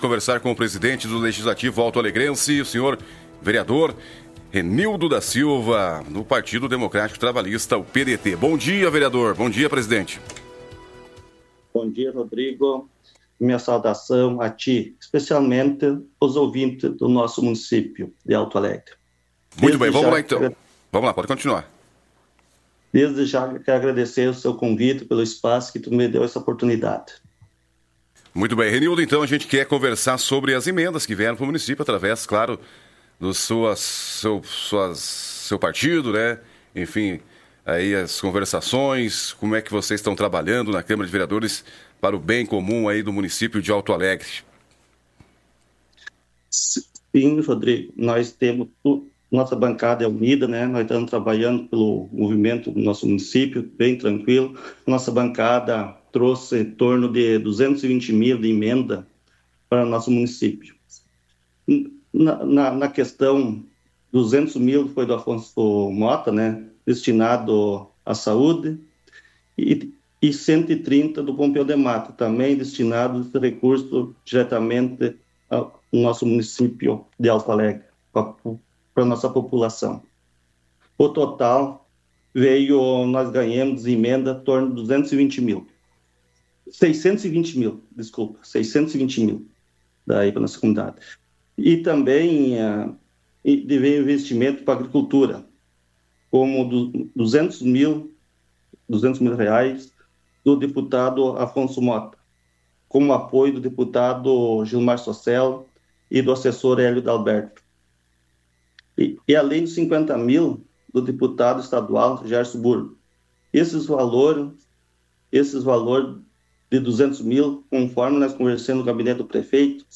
O presidente da Câmara de Vereadores de Alto Alegre, Renildo da Silva, concedeu entrevista ao programa Giro da Notícia e destacou a conquista de importantes recursos destinados ao município por meio de emendas parlamentares.